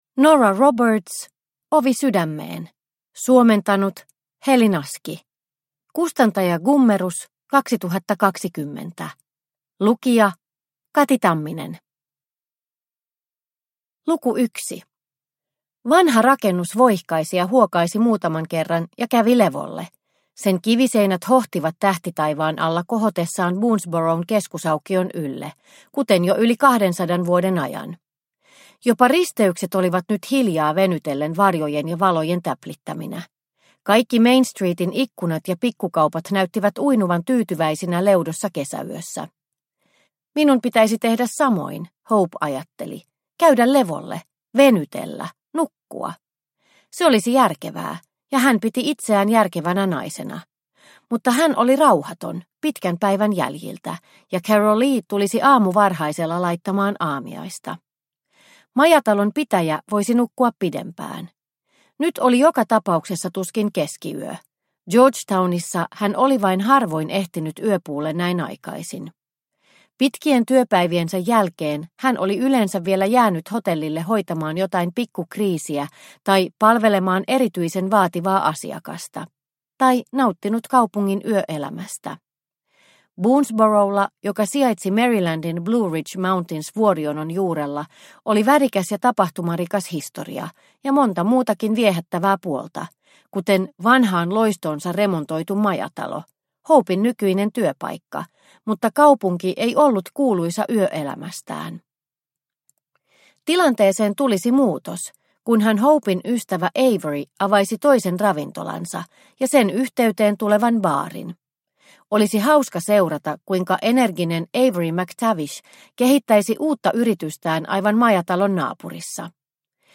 Ovi sydämeen – Ljudbok – Laddas ner